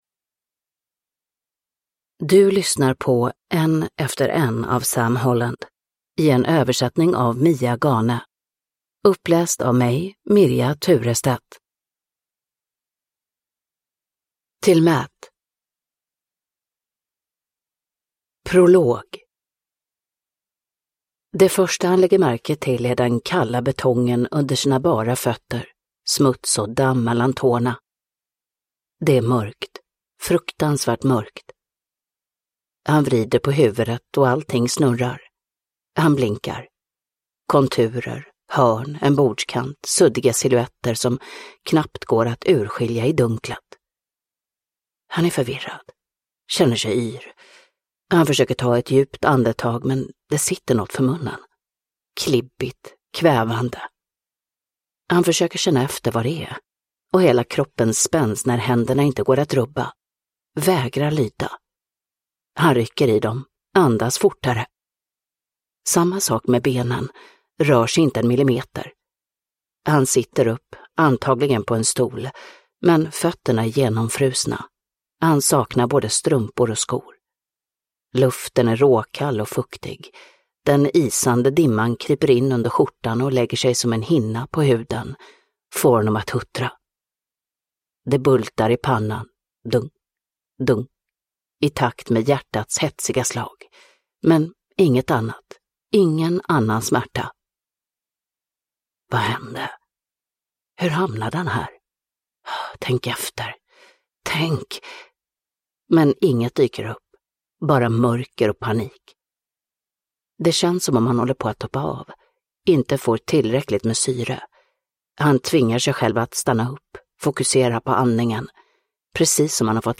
Uppläsare: Mirja Turestedt
Ljudbok